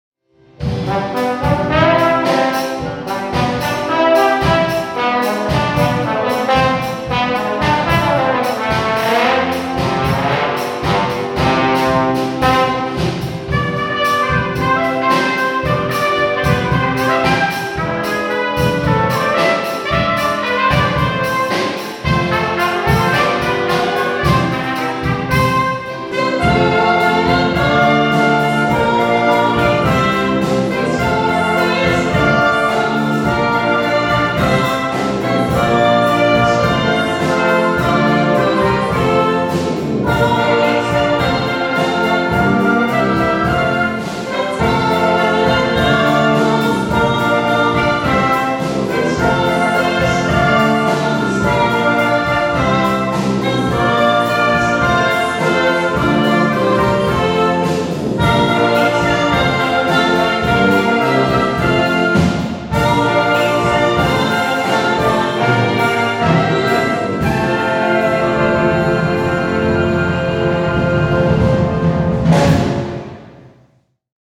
Das Musiktheater, welche Lehrpersonen der Musikschule Muri-Gümligen eigens für die Kinder der Schule Muri bei Bern einübten und aufführten, stellt auf phantasievolle und spielerische Art diverse Blasinstrumente vor.
Eine kleine Hörprobe vom Schlusssong, aufgezeichnet an der Hauptobe am Samstagvormittag, 27.10.18, können Sie